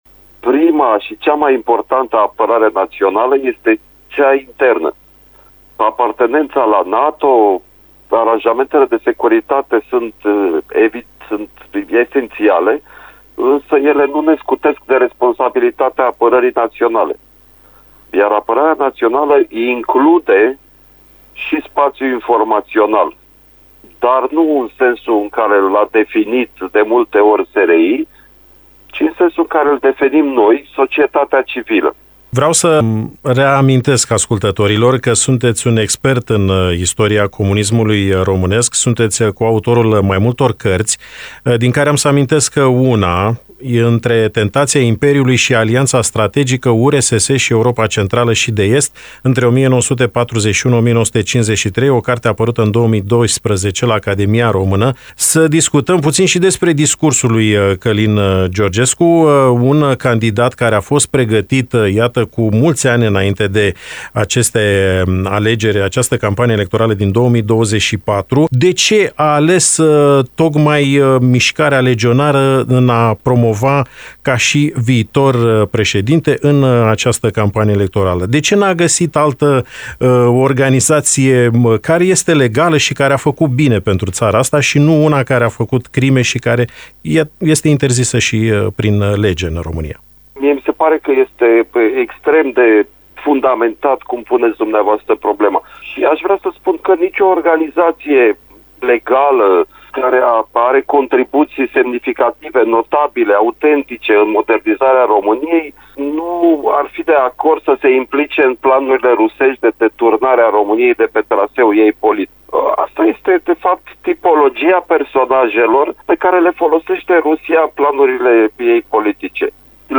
invitat la “Interviul dimineții”